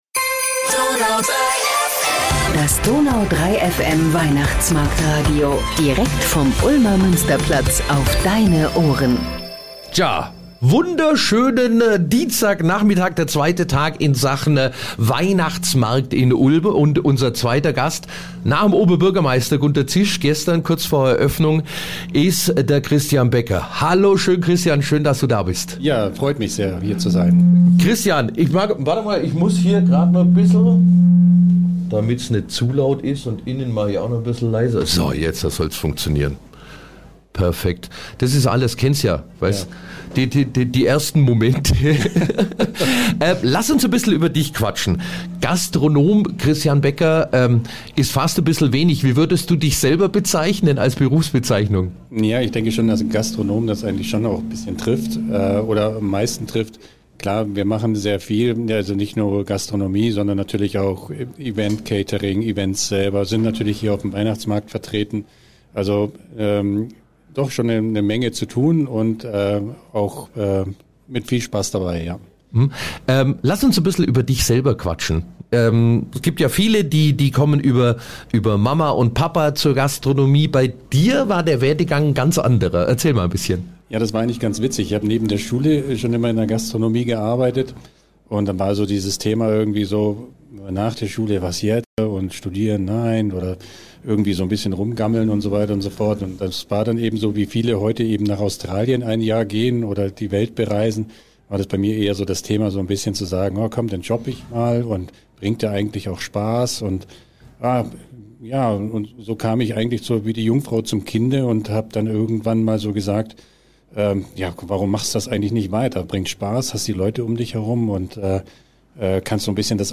Darüber sprechen wir mit ihm in unserem Weihnachtsmarkt-Radio.